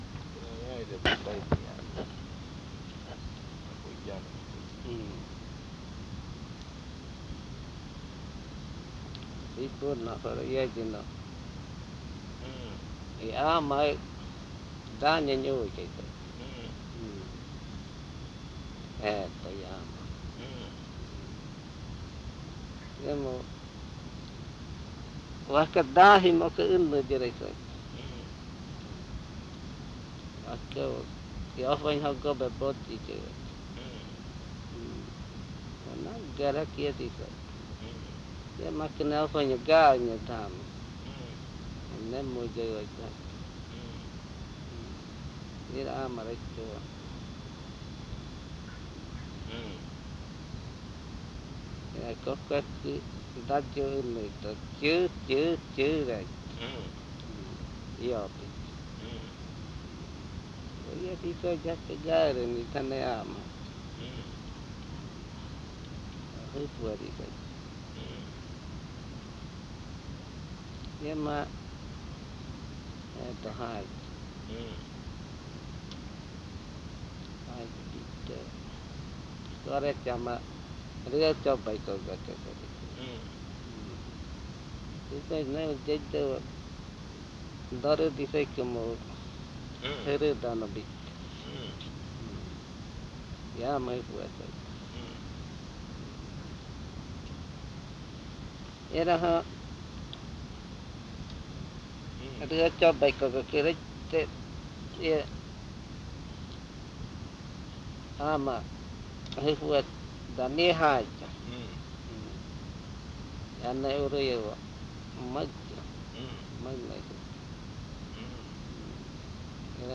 Adofikɨ (Cordillera), río Igaraparaná, Amazonas